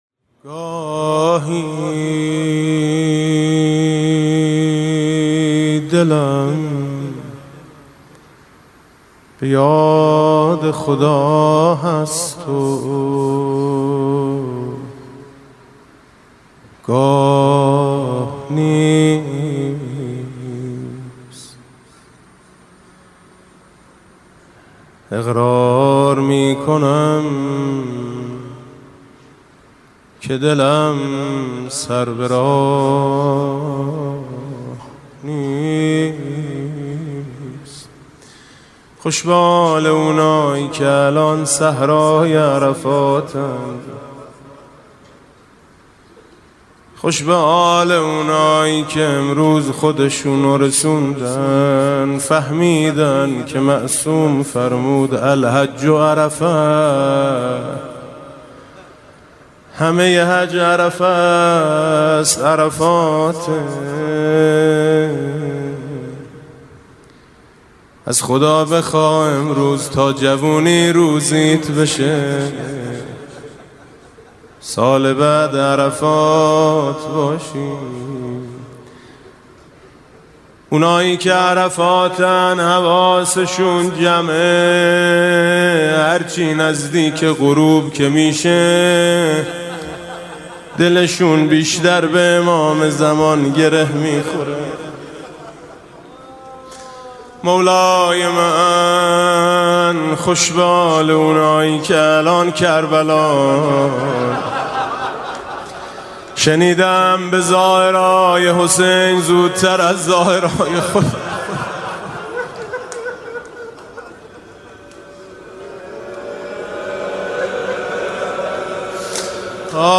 [آستان مقدس امامزاده قاضي الصابر (ع)]
مناسبت: قرائت دعای عرفه
با نوای: حاج میثم مطیعی